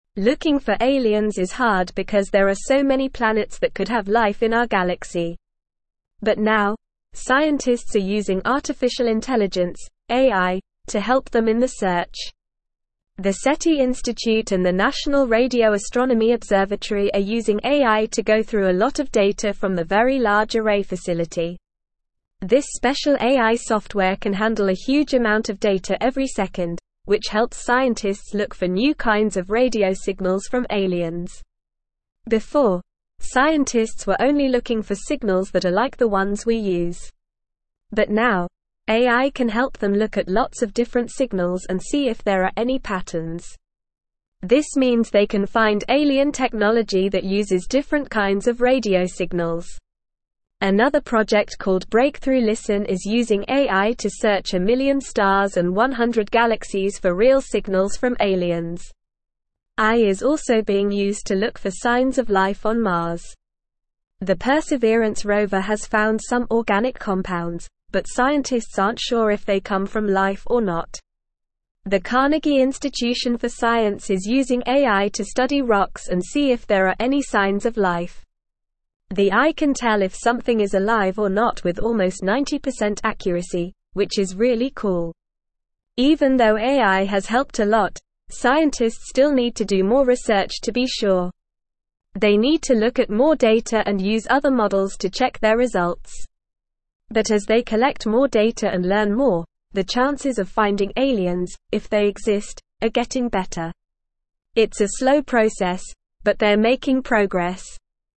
Normal
English-Newsroom-Upper-Intermediate-NORMAL-Reading-AI-Revolutionizes-Search-for-Extraterrestrial-Life.mp3